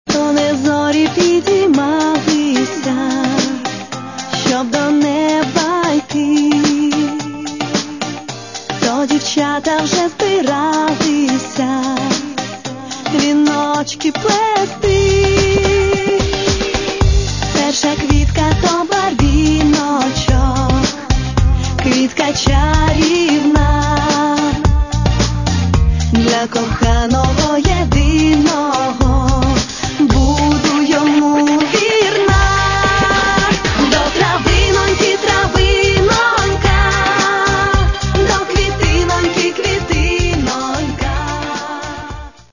Каталог -> MP3-CD -> Эстрада